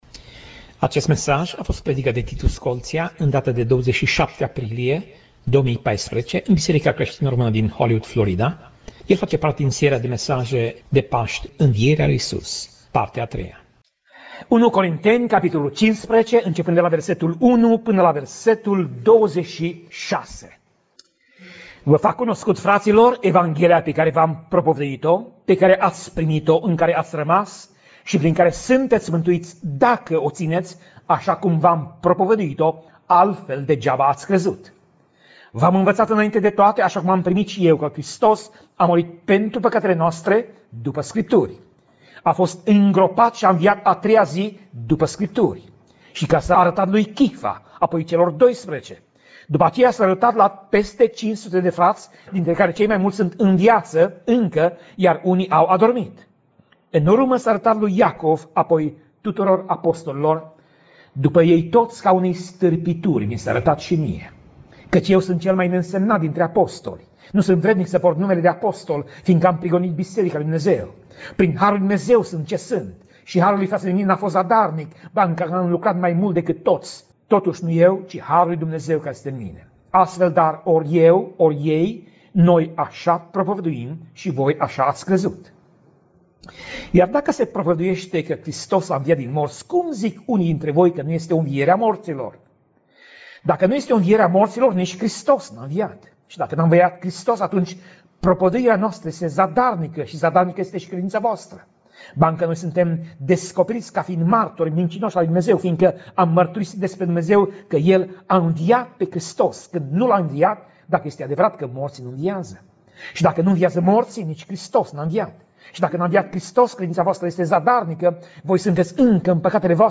Pasaj Biblie: 1 Corinteni 15:1 - 1 Corinteni 15:11 Tip Mesaj: Predica